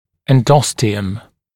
[enˈdɔstɪəm][энˈдостиэм]эндост (внутренняя оболочка костно-мозговой полости)